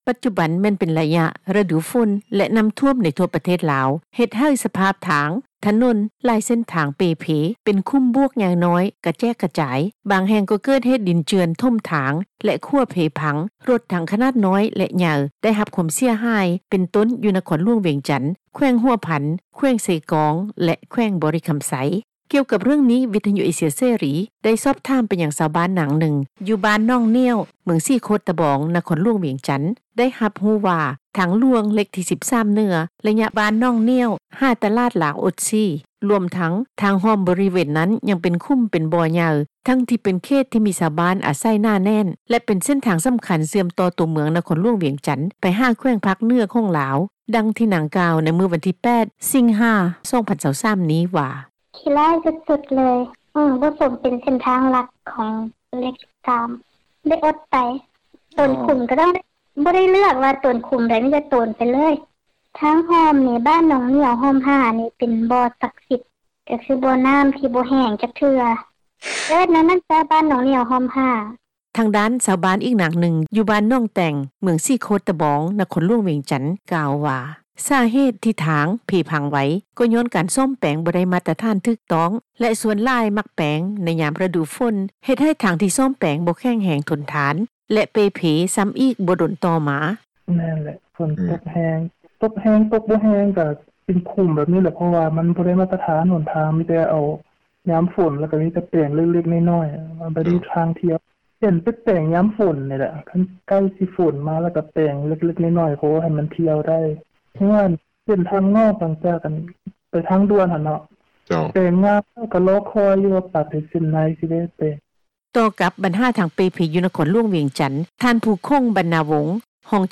ກ່ຽວກັບເຣື່ອງນີ້, ວິທຍຸເອເຊັຽເສຣີ ໄດ້ສອບຖາມໄປຍັງ ຊາວບ້ານ ນາງນຶ່ງ ຢູ່ບ້ານໜອງໜ້ຽວ ເມືອງສີໂຄດຕະບອງ ນະຄອນຫຼວງວຽງ ໄດ້ຮັບຮູ້ວ່າ ທາງຫຼວງເລຂທີ 13 ເໜືອ ໄລຍະບ້ານໜອງໜ້ຽວ ຫາຕລາດລາວ-ອົດຊີ ລວມທັງທາງຮ່ອມບໍຣິເວນນັ້ນ ຍັງເປັນຂຸມເປັນບໍ່ໃຫຍ່ ທັງທີ່ເປັນເຂດ ທີ່ມີຊາວບ້ານອາໄສໜາແໜ້ນ ແລະ ເປັນ ເສັ້ນທາງສຳຄັນເຊື່ອມຕໍ່ ຕົວເມືອງນະຄອນຫຼວງວຽງຈັນ ໄປຫາ ແຂວງພາກເໜືອ ຂອງລາວ.